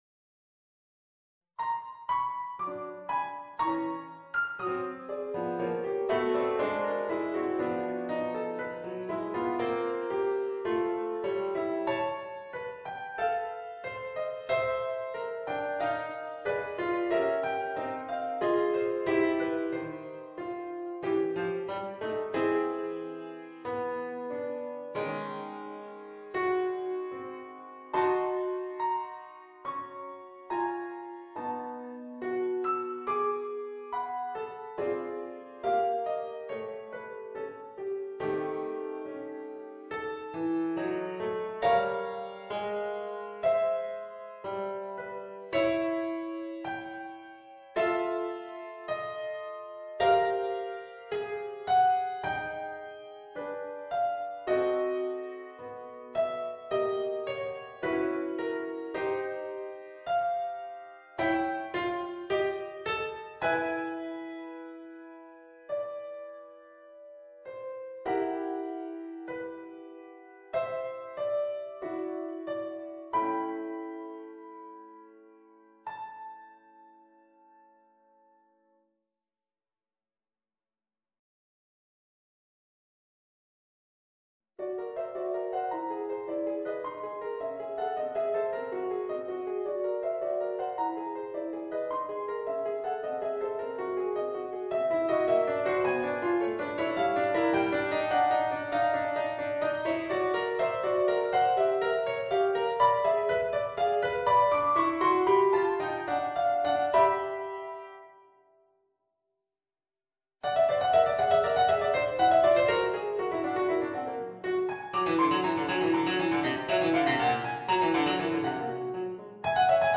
Voicing: Piano Solo